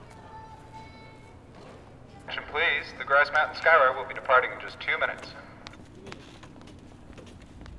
WORLD SOUNDSCAPE PROJECT TAPE LIBRARY
9. announcement, female voice